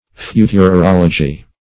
futurology - definition of futurology - synonyms, pronunciation, spelling from Free Dictionary
futurology \fu`tur*ol"o*gy\, n.